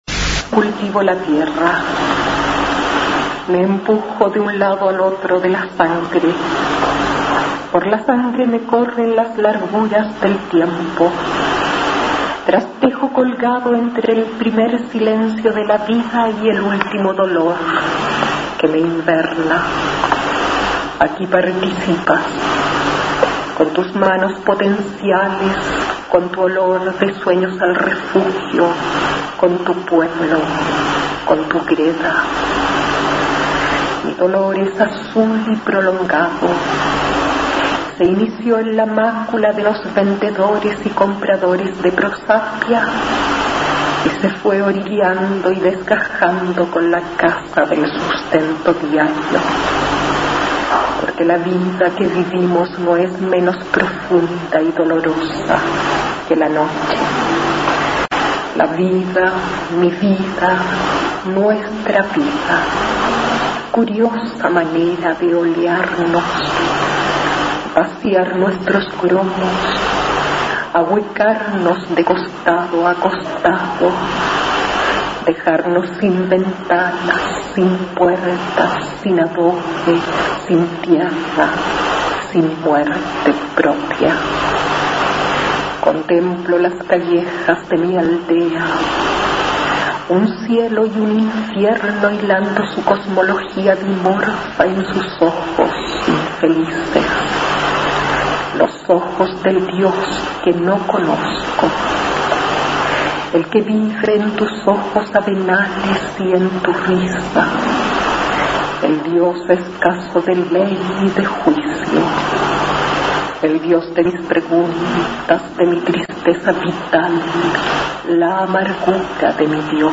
Aquí se puede escuchar a la autora nacional Astrid Fugellie recitando su poema Cultivo la tierra, del libro "Las jornadas del silencio" (1984).